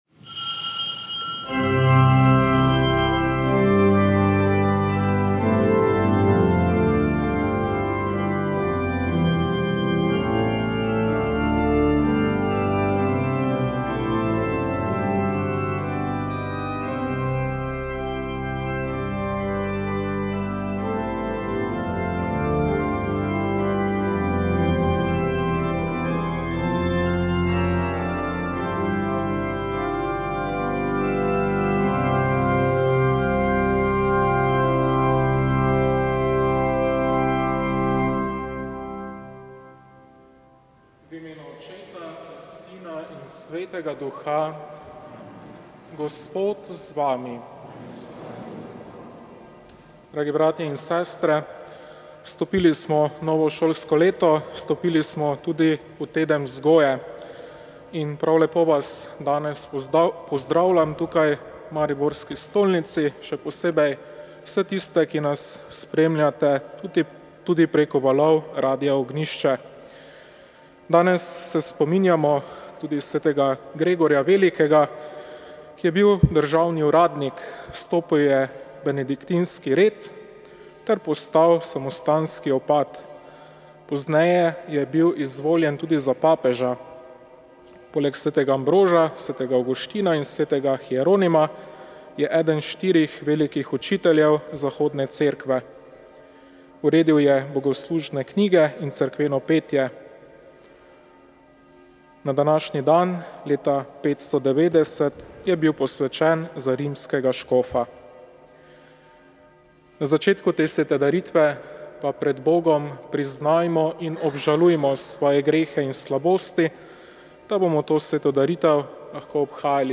Sv. maša iz cerkve sv. Trojice v Odrancih 29. 9.
Radio Ognjišče duhovnost masa Sveta maša VEČ ...